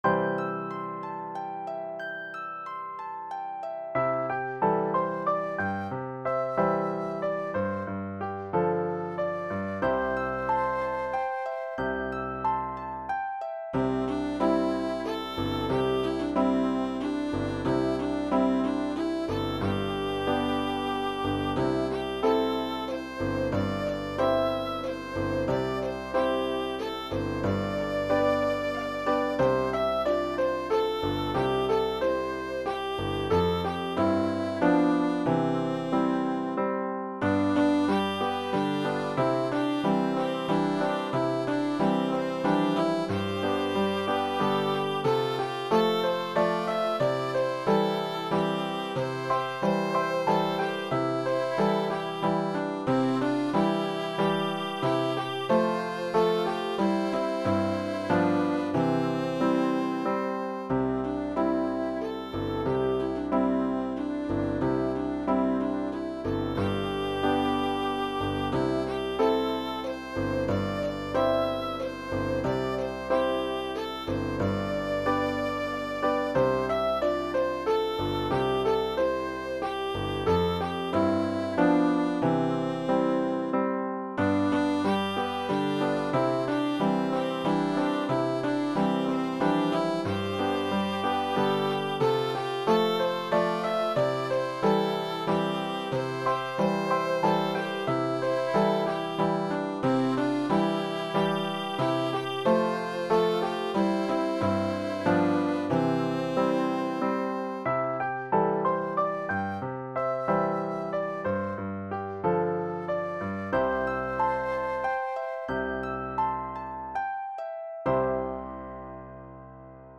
しっとりと聞かせられるといいですね。
現在、オカリナ 尺八 フルート 篠笛 リコーダー クラリネット EWI ウクレレ ハープ ヴァイオリン ピアノ 17名で活動中です。
木管アンサンブル 弦楽合奏団